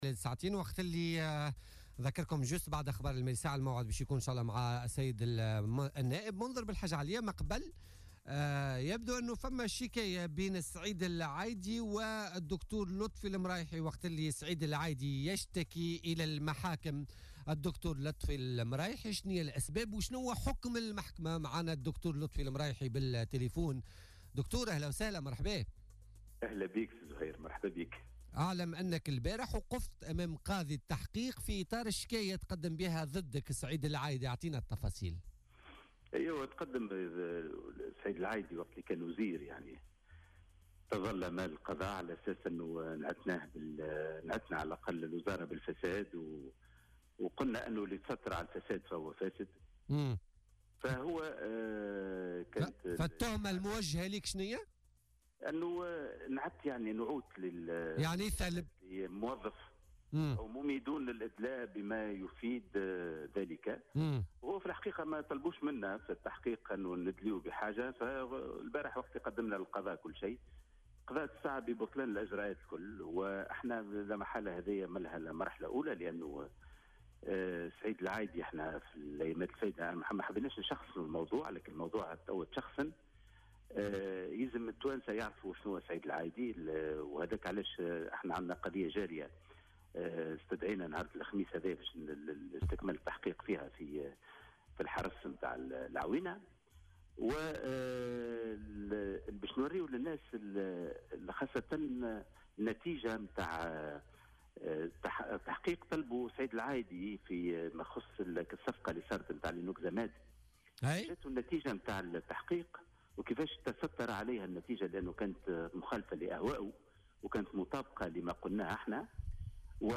قال الأمين العام لحزب الاتحاد الشعبي الجهوري لطفي المرايحي في مداخلة له في بولتيكا اليوم الثلاثاء إنه مثل أمام القضاء أمس للاستماع لأقواله في قضية كان قد رفعها ضد سعيد العايدي وزير الصحة الأسبق تتعلق بثلب ونسبة تهم باطلة دون تقديم الأدلة.